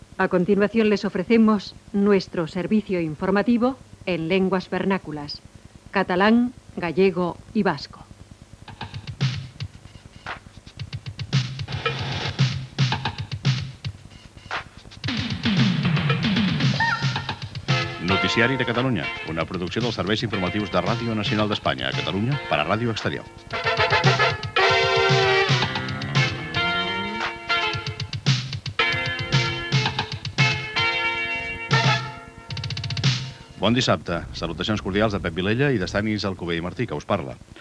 Anunci en castellà dels espais en "lenguas vernáculas", sintonia i inici del Noticiari de Catalunya, amb els noms de l'equip
Informatiu